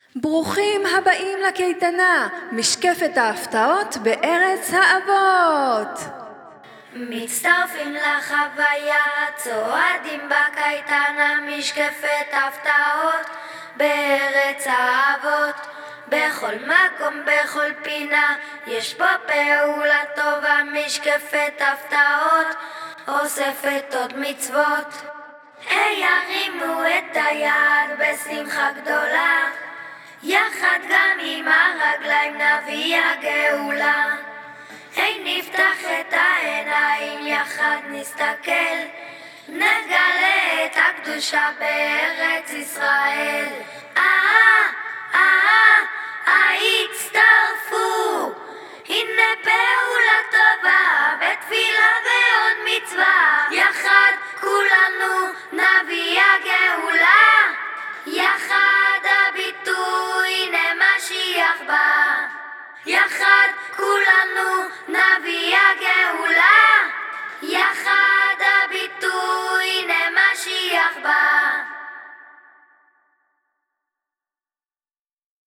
המנון הקייטנה – ווקאלי
המנון-הקייטנה-ווקאלי.mp3